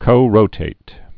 (kō-rōtāt)